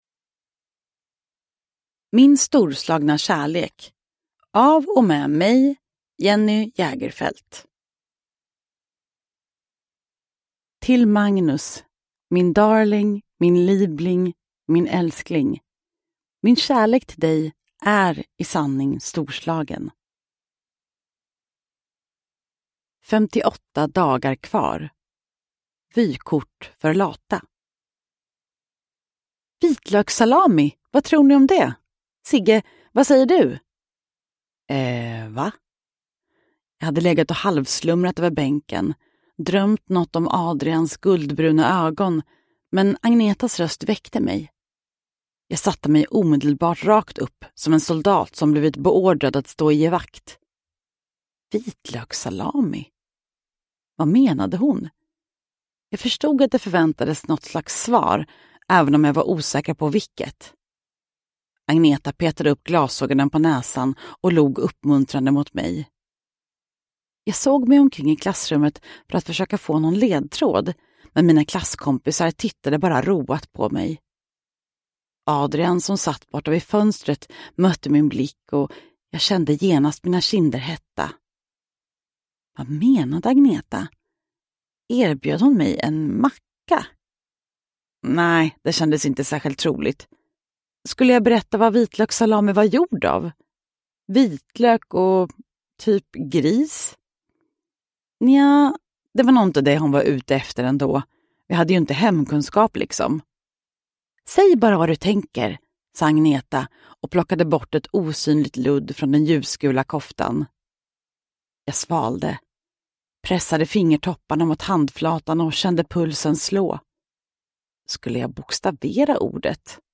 Uppläsare: Jenny Jägerfeld